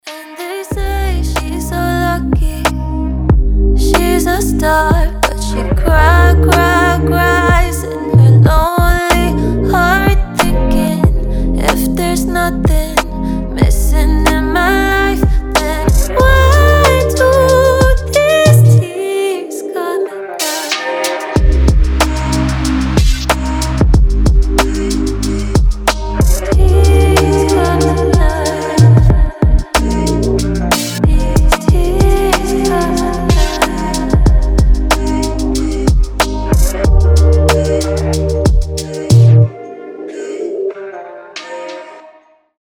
• Качество: 320, Stereo
грустные
женский голос
Cover
медленные
Chill Trap
соул